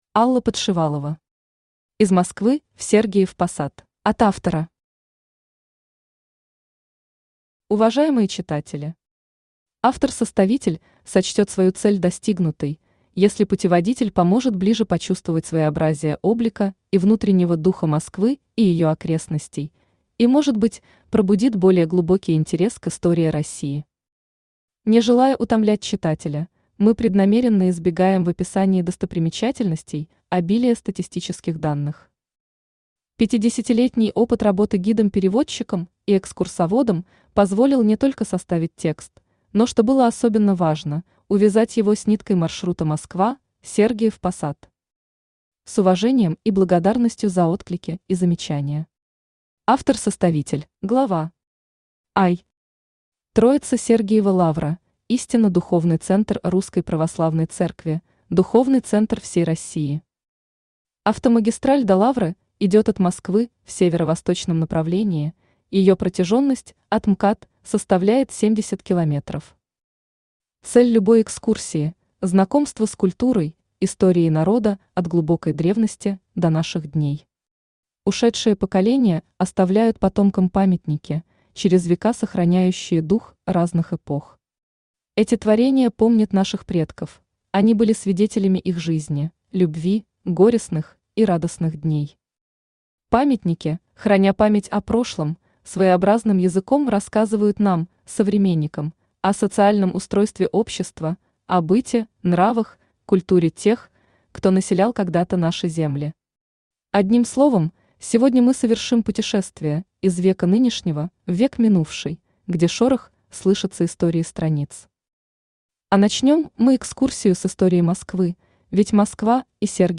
Аудиокнига Из Москвы в Сергиев Посад | Библиотека аудиокниг
Aудиокнига Из Москвы в Сергиев Посад Автор Алла Владимировна Подшивалова Читает аудиокнигу Авточтец ЛитРес.